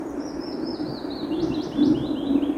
Willow Warbler, Phylloscopus trochilus
StatusSinging male in breeding season